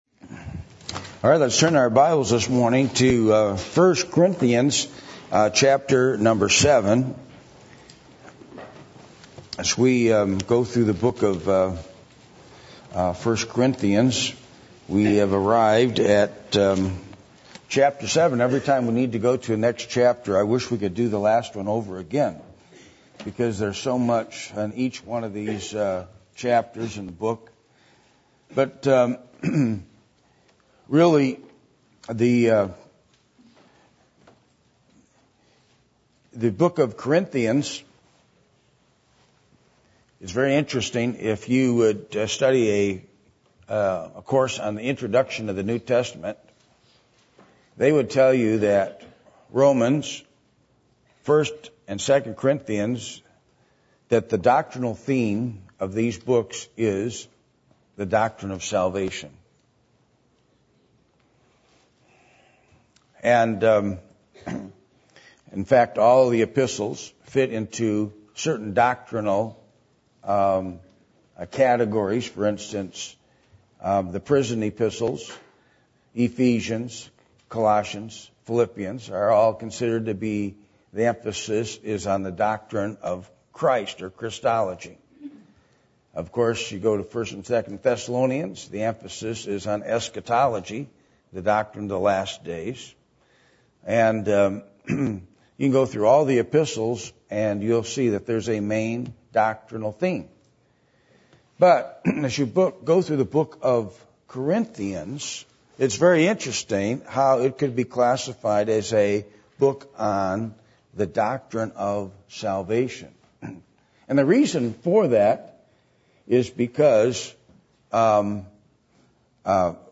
Passage: 1 Corinthians 7:1-40 Service Type: Sunday Morning